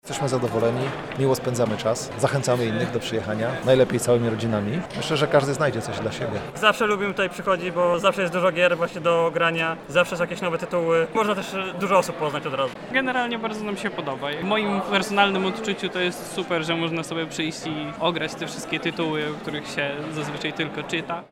Czy warto wziąć udział w imprezie? O to zapytaliśmy graczy: